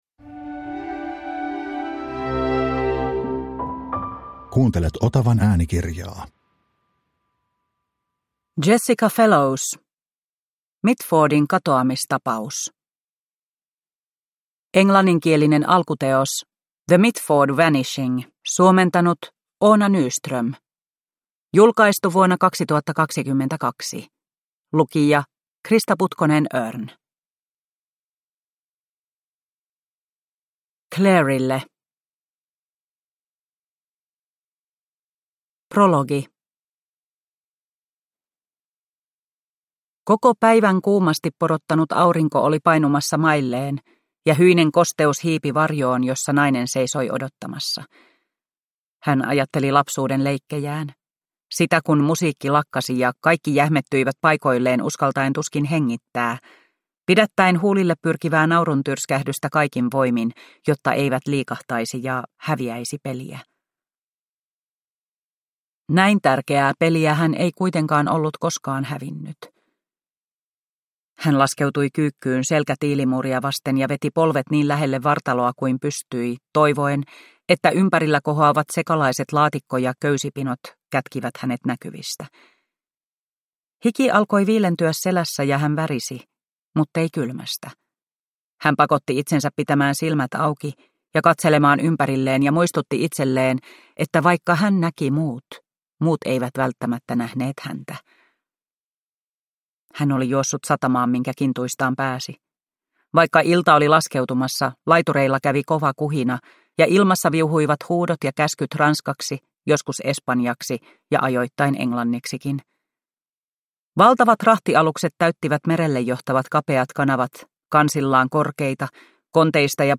Mitfordin katoamistapaus – Ljudbok – Laddas ner